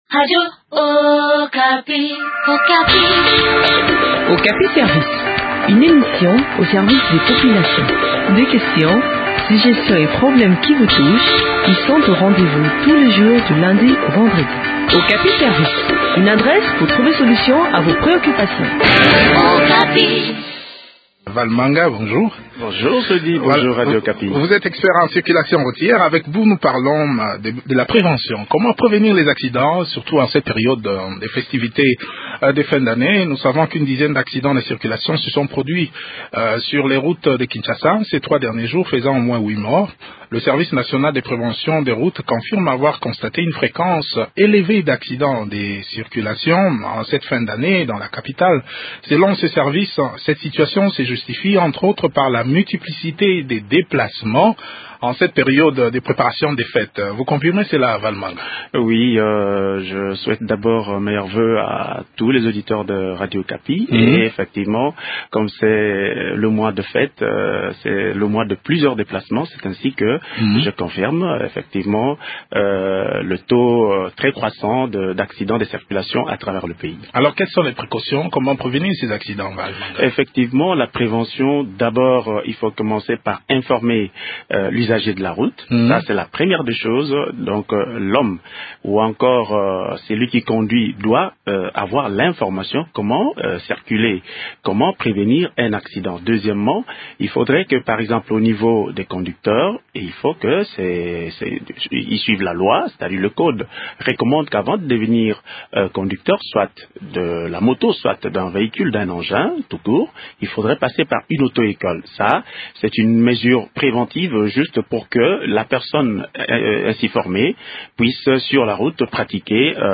Expert en circulation routière